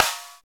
SNR STICK ME.wav